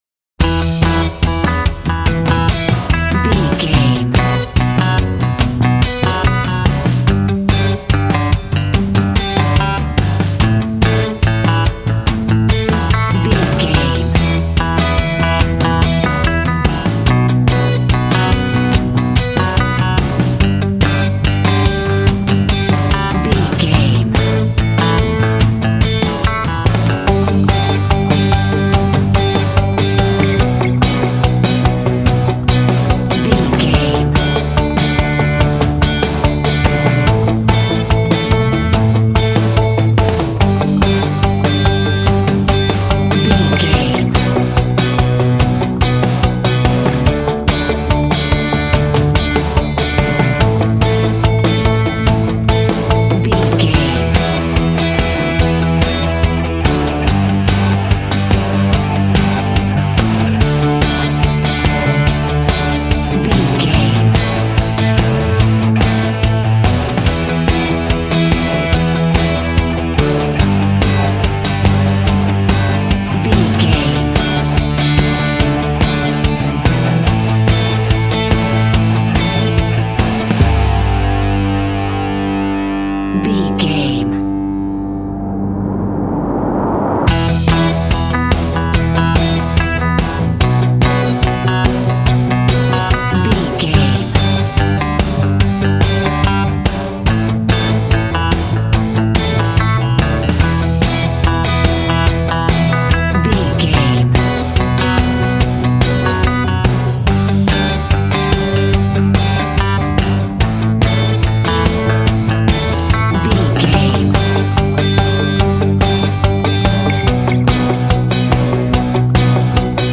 Aeolian/Minor
groovy
powerful
fun
organ
drums
bass guitar
electric guitar
piano